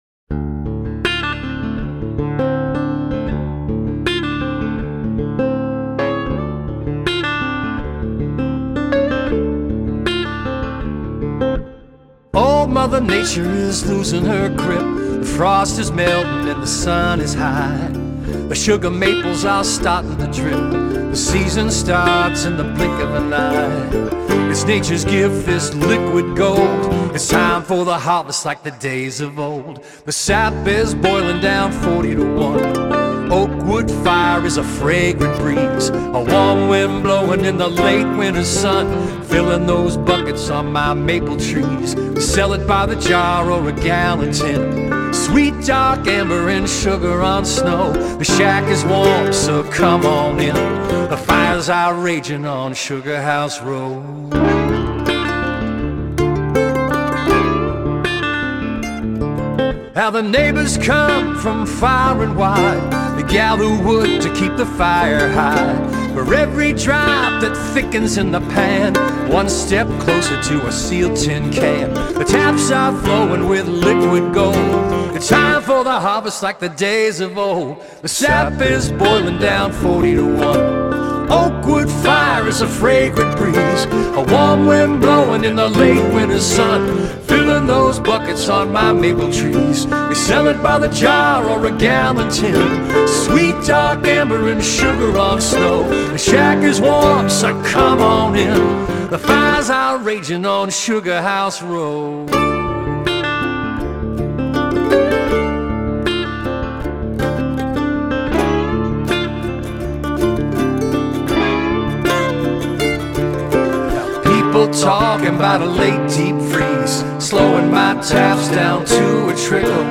a full time singer-songwriter based in Denmark Maine.